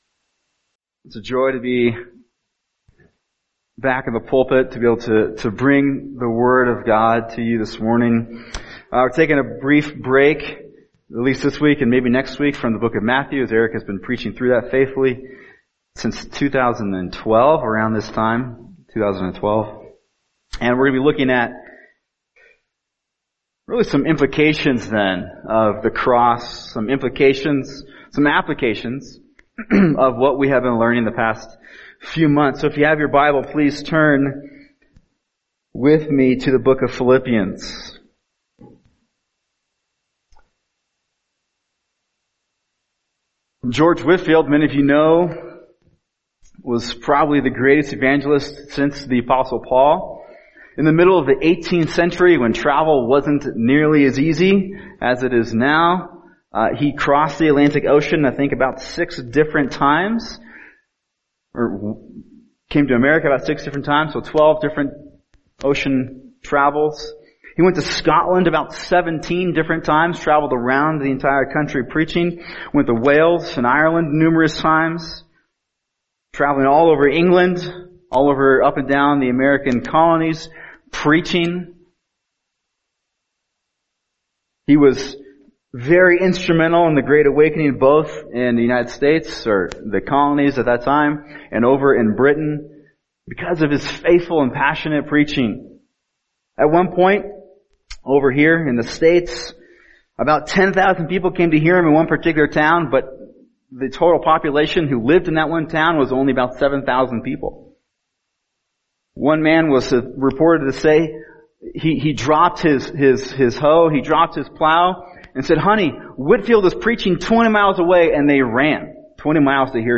[sermon] Philippians 3:12-16 Sprinting Toward Holiness | Cornerstone Church - Jackson Hole